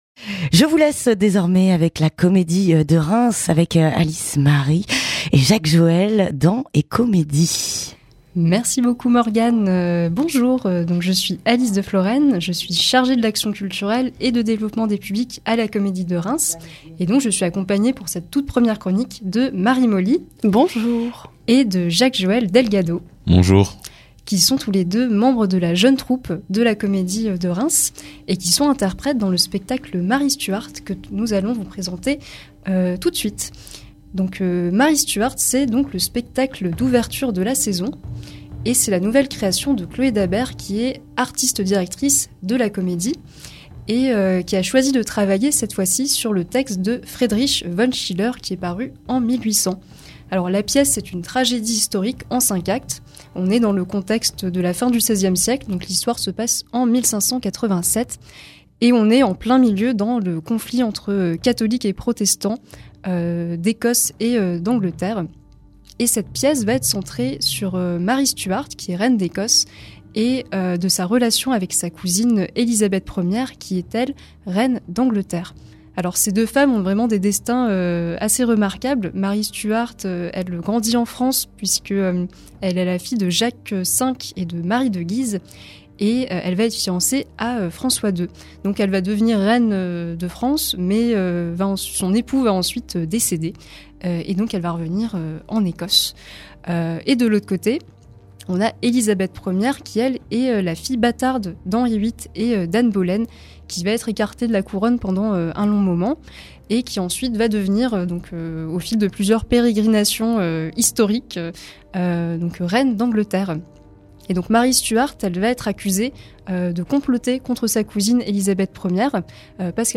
Chronique du 24 septembre (9:50)